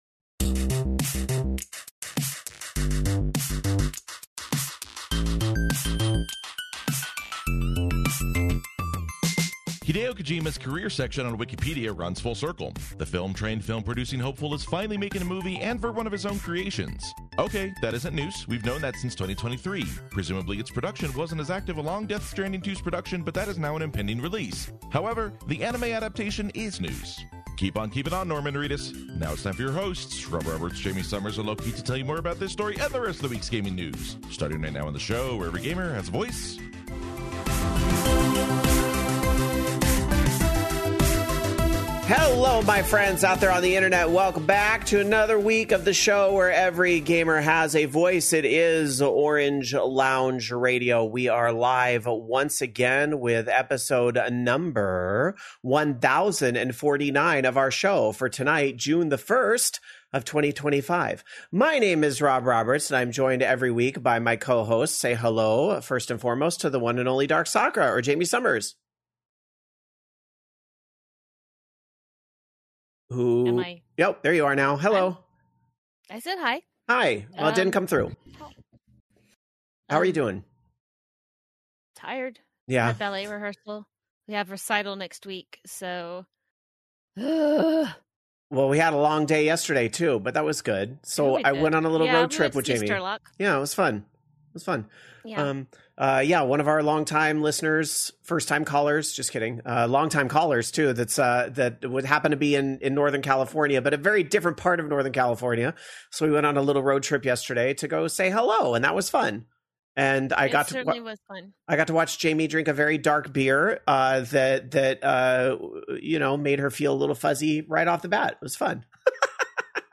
The longest running live show and podcast about video games on the internet. An independent voice in a crowded world of video game podcasts, Orange Lounge Radio is dedicated to giving a forum for every gamer to have their voice.
Tapes live on Sunday nights on the VOG Network.